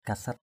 /ka-sat/ (d. t.) vinh dự, vinh hạnh, chức vụ cao = honneurs, charges, dignités. jieng kasat j`$ kxT được vinh danh, thành đạt = arriver aux honneurs. 2.